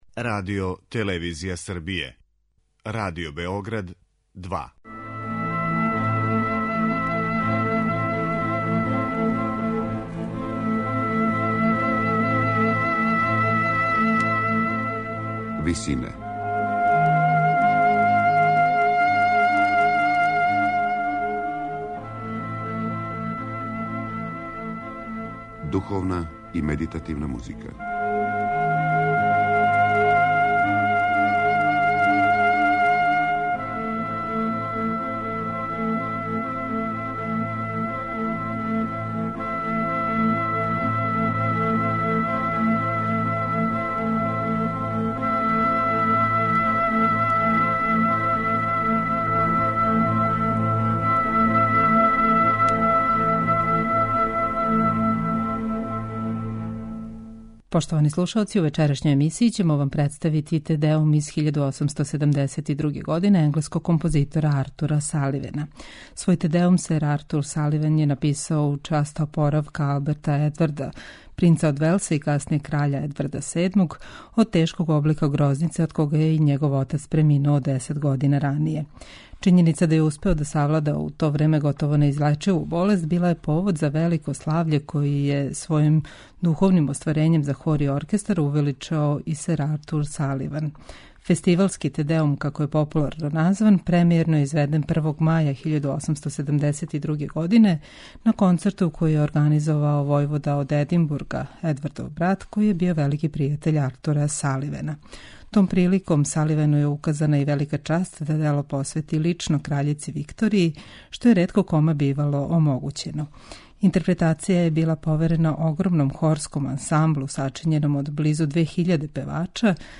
Дело је изразито радосног карактера и слави оздрављење младог племића.